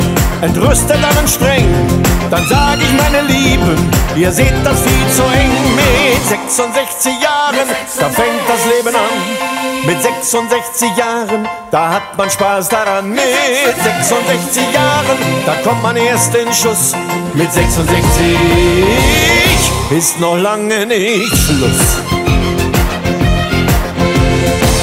Gattung: Moderner Einzeltitel (mit Gesang)
Besetzung: Blasorchester
für Blasorchester (mit Gesang).